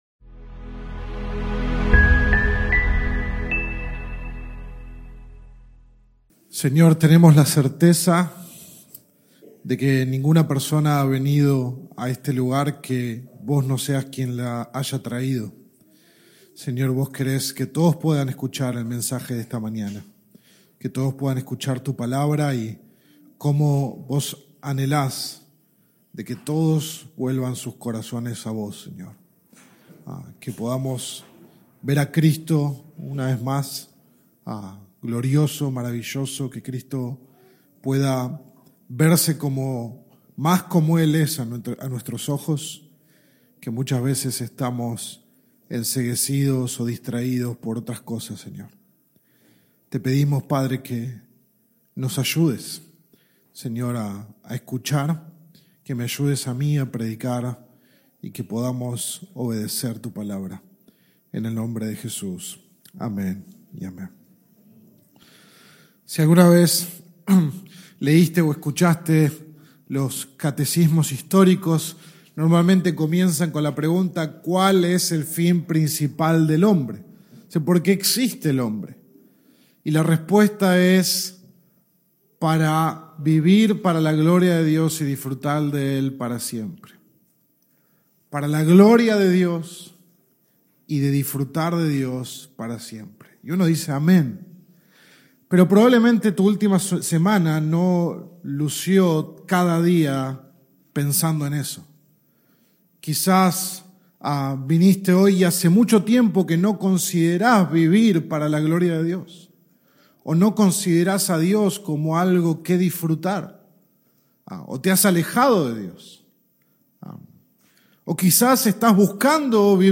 Sermón 22 de 33 en Sermones Individuales